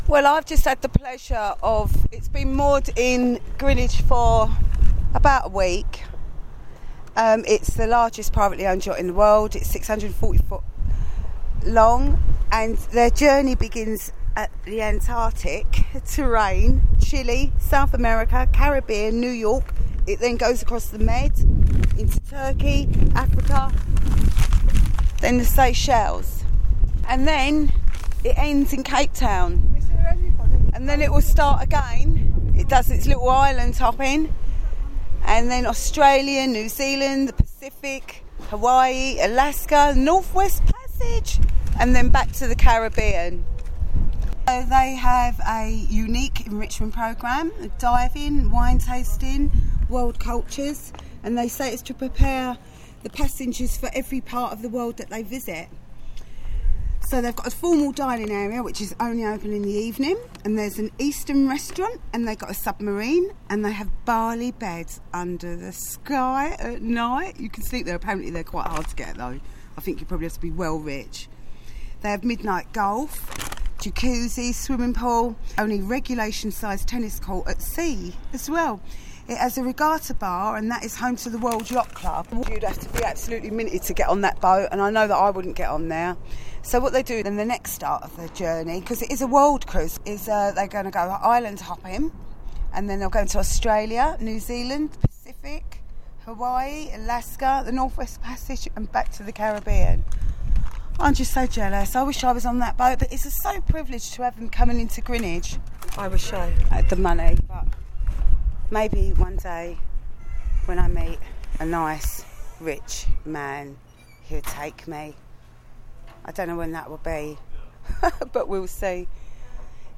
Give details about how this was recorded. I watch The World pass me by literally, I'm in Greenwich watching the worlds largest yatch continuing on it's journey. sorry for quality it was very windy on the River Thames.